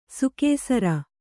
♪ sukēsara